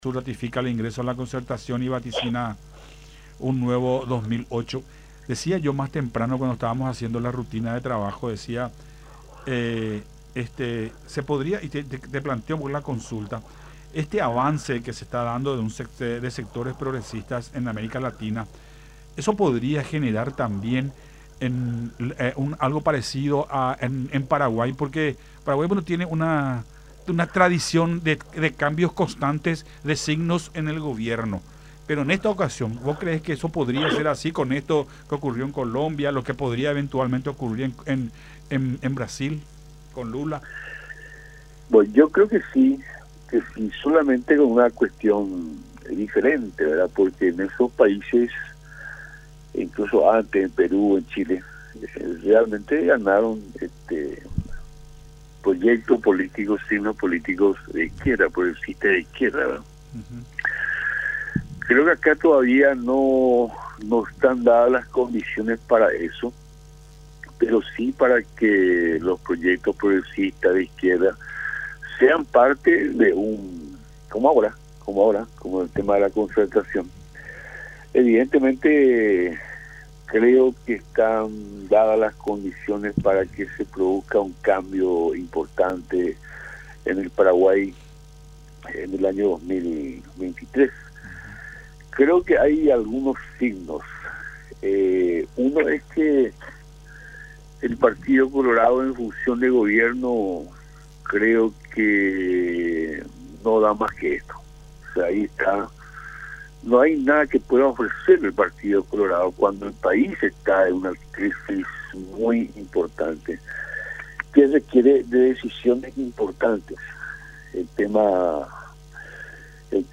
La concertación es un amplio y difícil diálogo que hay que saber administrarlo”, manifestó en diálogo con Nuestra Mañana a través de Unión TV.